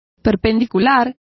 Complete with pronunciation of the translation of perpendicular.